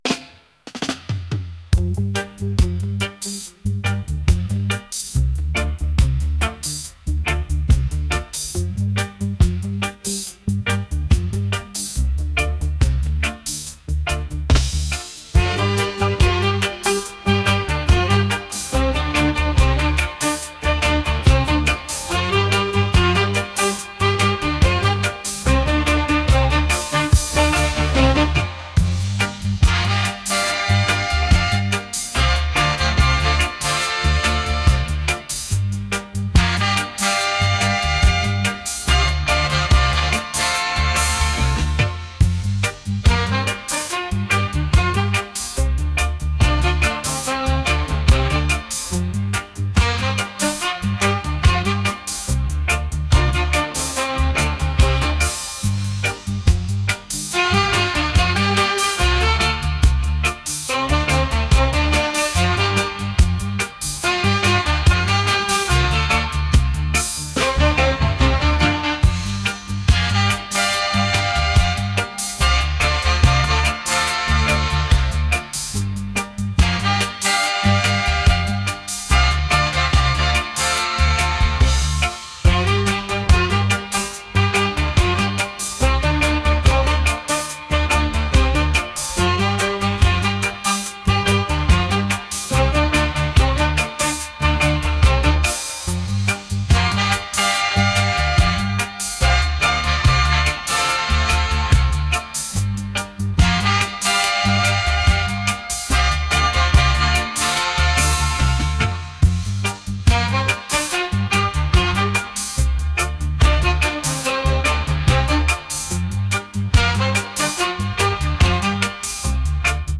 reggae | pop | lofi & chill beats